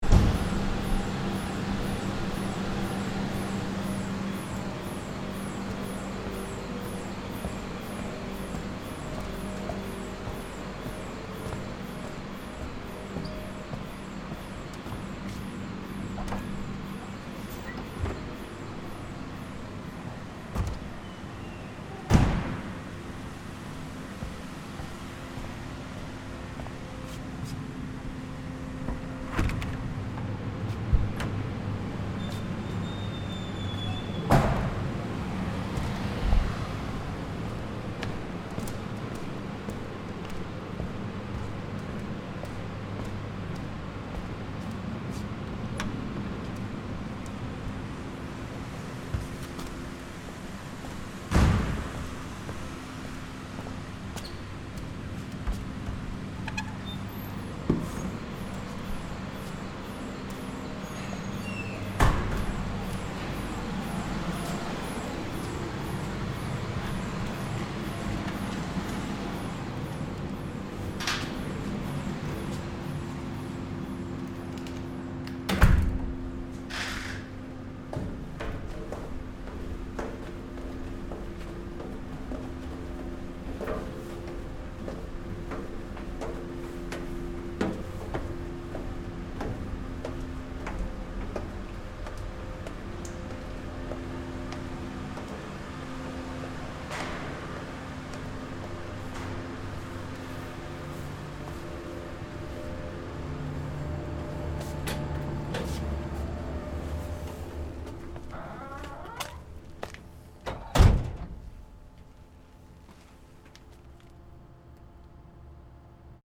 ss-ls-ntt-inside_stereo.mp3